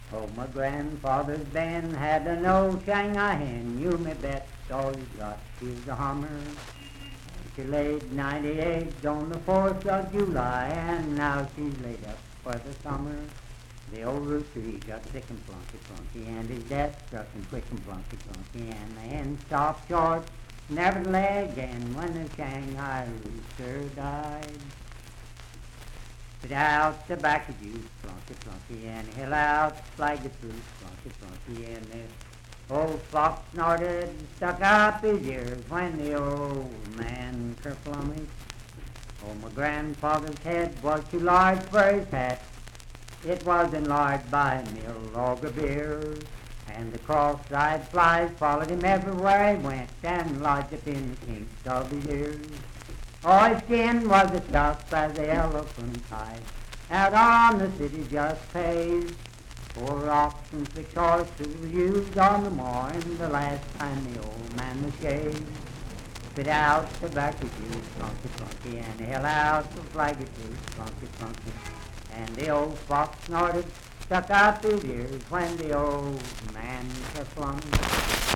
Unaccompanied vocal music
Verse-refrain 3(4) & R(4).
Voice (sung)
Parkersburg (W. Va.), Wood County (W. Va.)